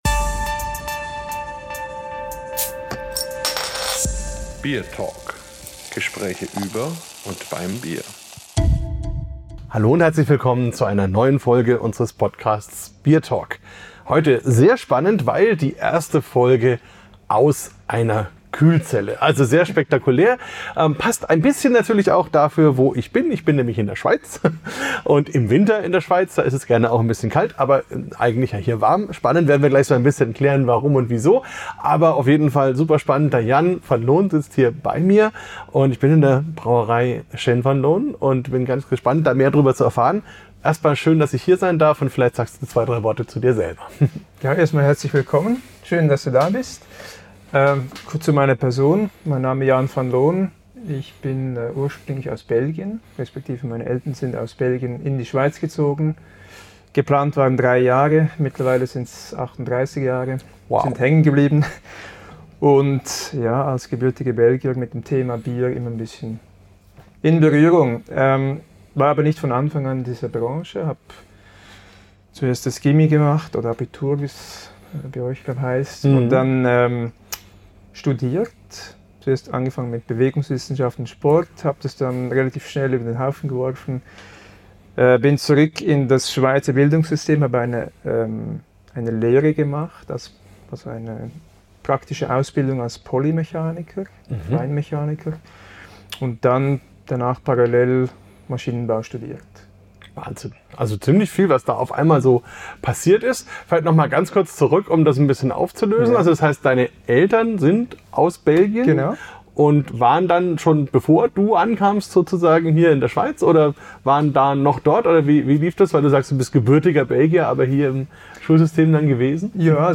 Nicht aus Show-Gründen, sondern weil nebenan gerade gebraut wird – und es hier drin herrlich ruhig ist.